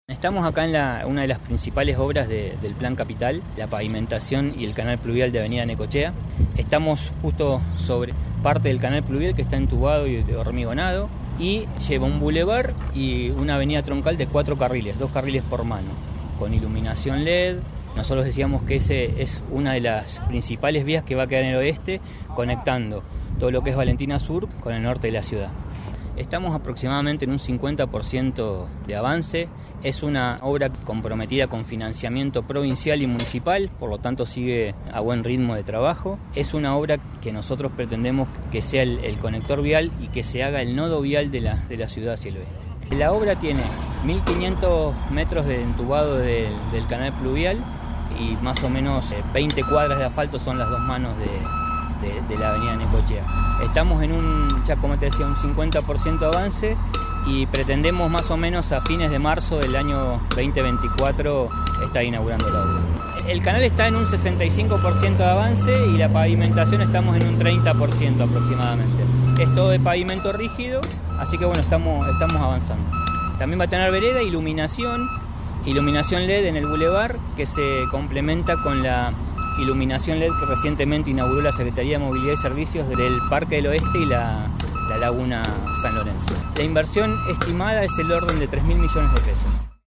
Pablo Vega, subsecretario de Coordinación y Seguimiento de Control de Gestión, recorrió esta mañana el sector y señaló que el canal está a un 65 por ciento de avance y la pavimentación en un 30.
Pablo Vega, subsecretario de Coordinación y Seguimiento de Control de Gestión.